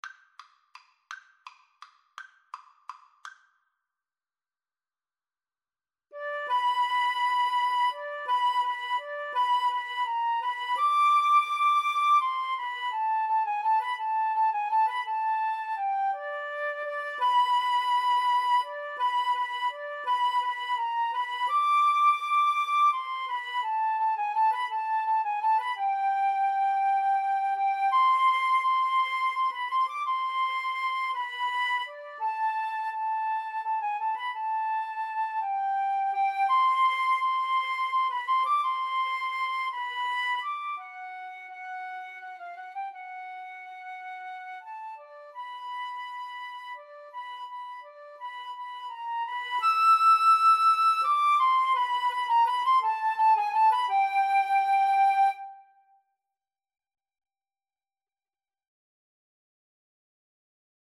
Free Sheet music for Flute Duet
3/4 (View more 3/4 Music)
G major (Sounding Pitch) (View more G major Music for Flute Duet )
Tempo di valse =168
Classical (View more Classical Flute Duet Music)